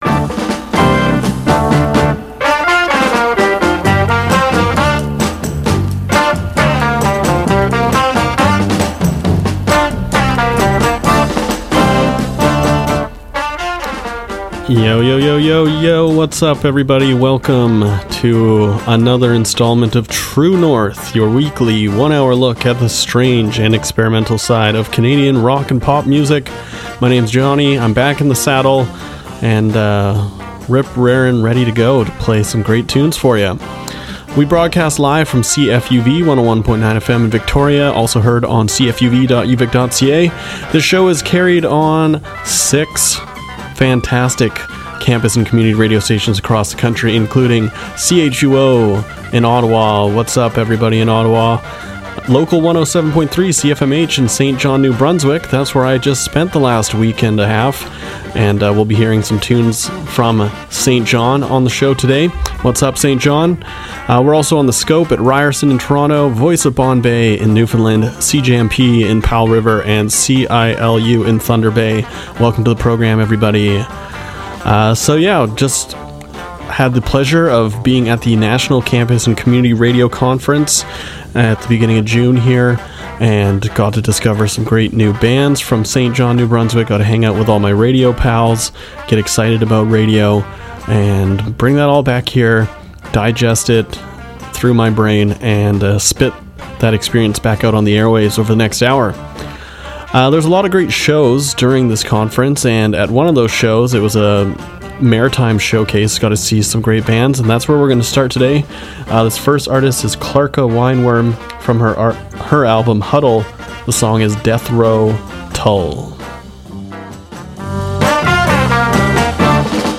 An hour of strange, experimental and independent Canadian rock and pop